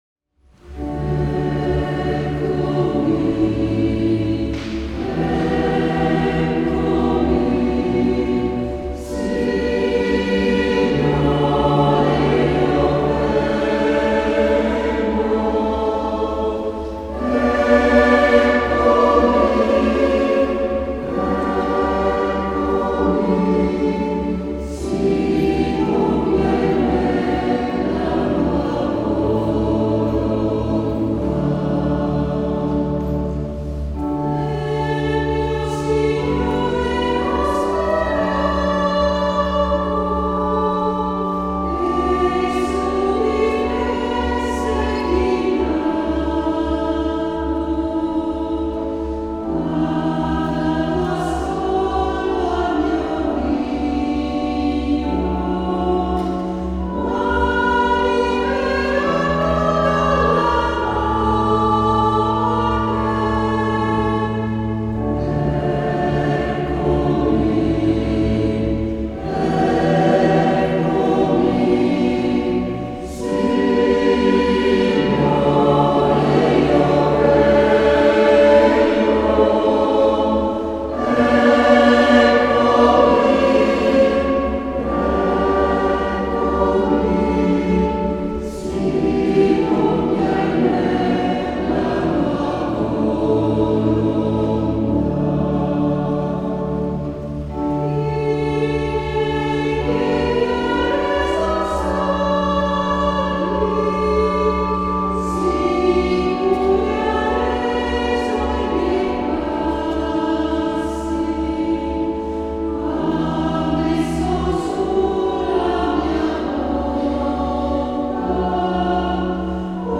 SCHOLA CANTORUM Sedico (Belluno)
Sedico 25_03_2025